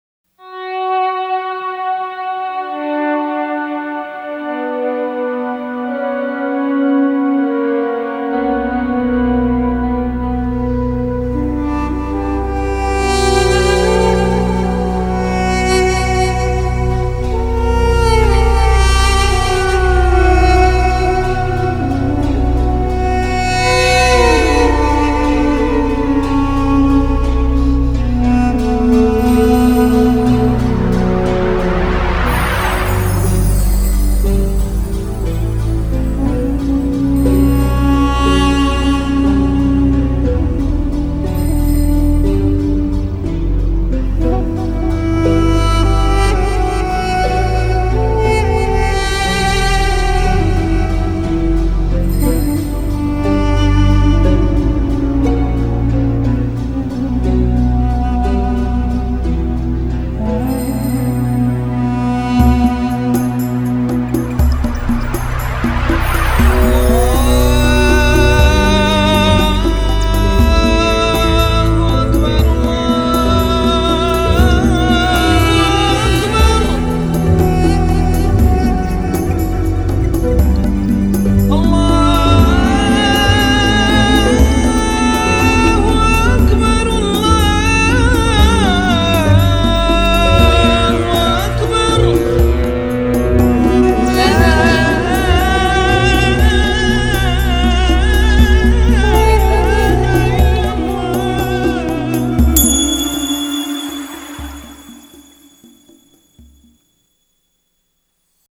World: Middle Eastern